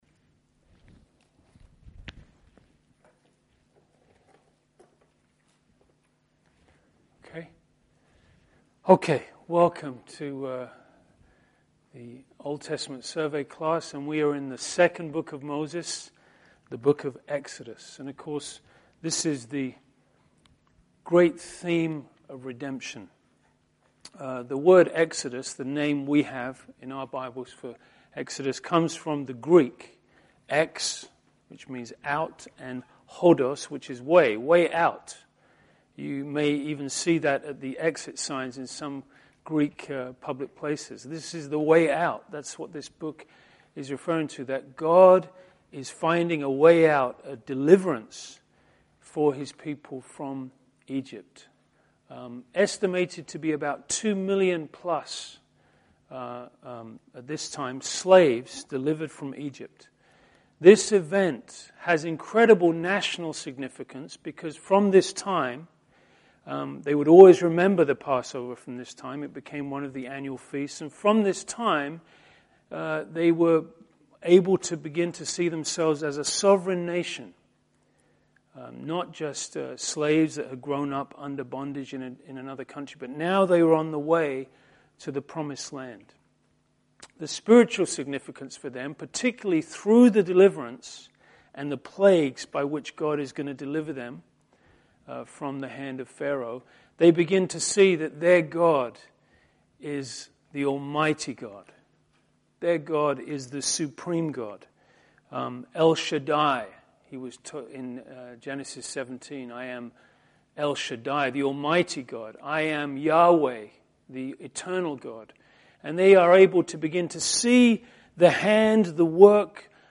Exodus Part 1 (Survey Class)